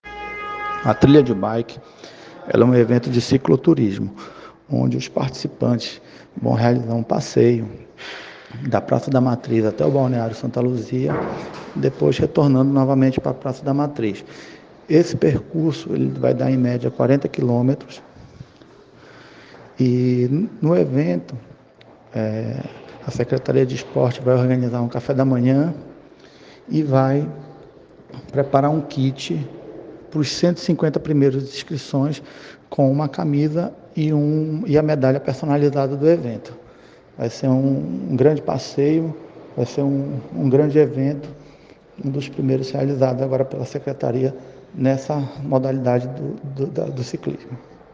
O Secretário de Esportes, Luiz Pinho, dá mais detalhes sobre o evento: